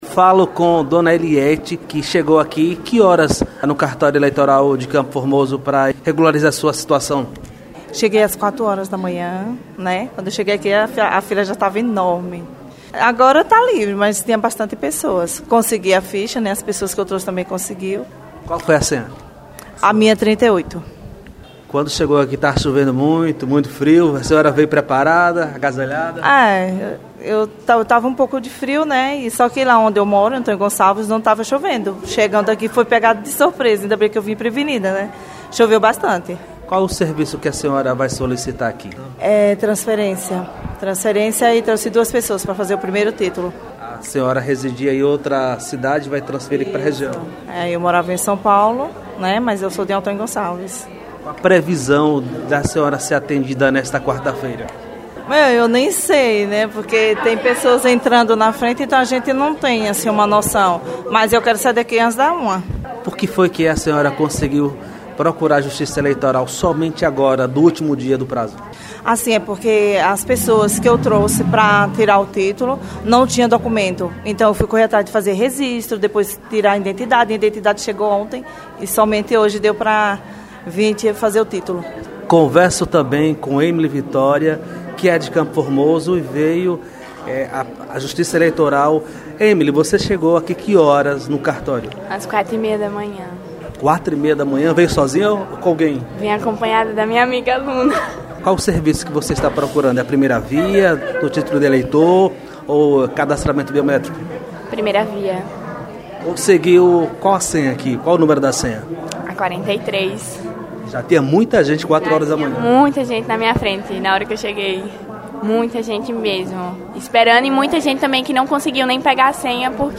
Reportagem com eleitores para regularizar biometria de títulos no cartório eleitoral de CF